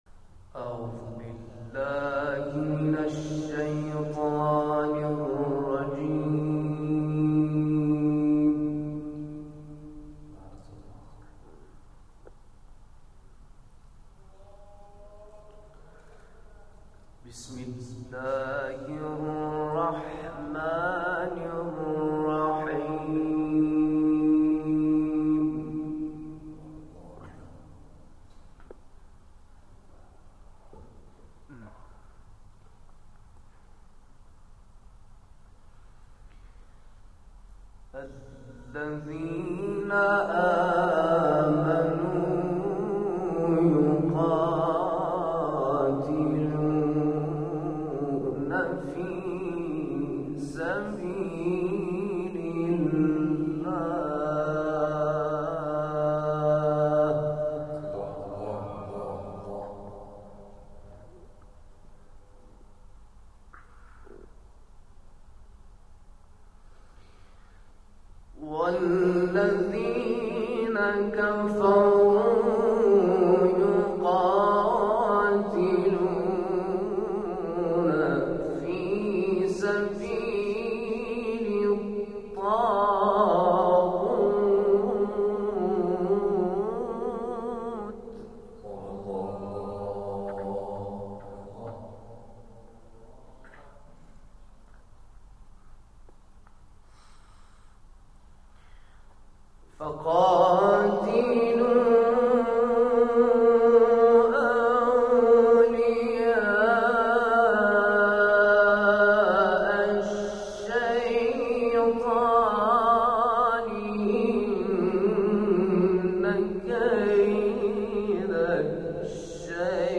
در ادامه، اعضای جلسه به تلاوت آیات ادامه درس جلسه و ارائه تلاوت‌های تقلیدی پرداختند.